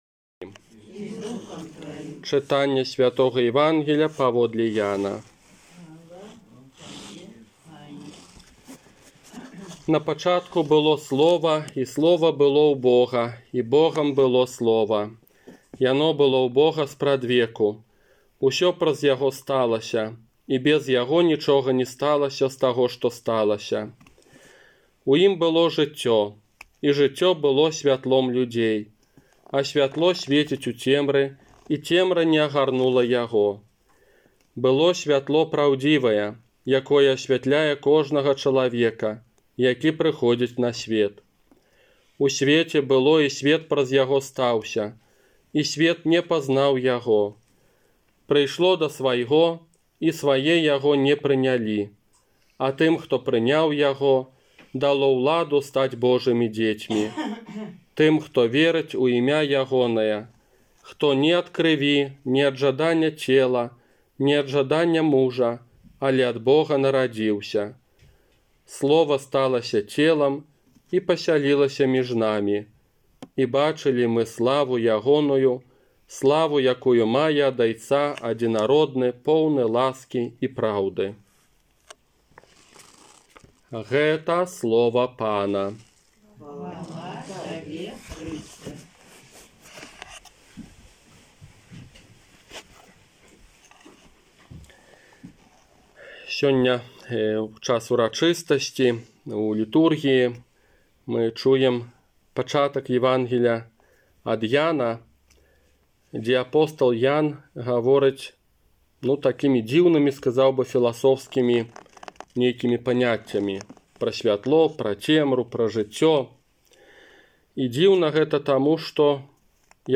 Казанне на Божае Нараджэнне 25 снежня 2020 года